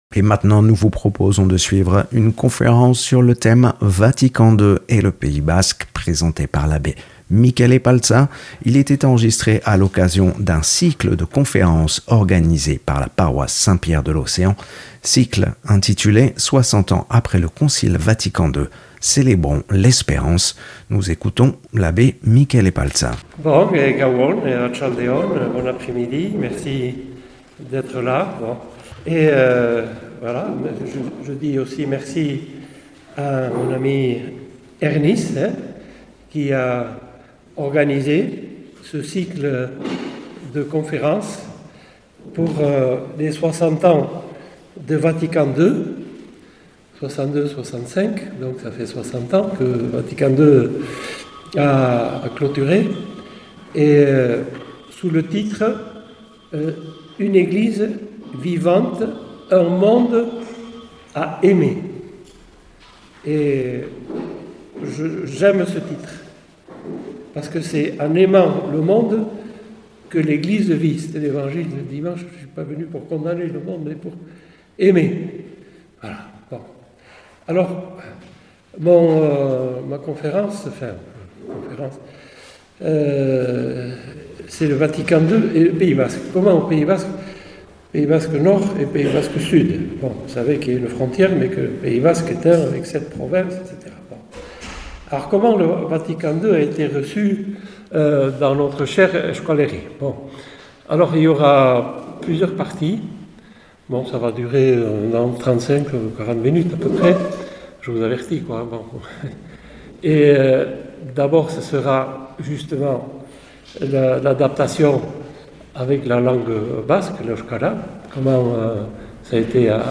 Vatican II et le Pays-Basque, une conférence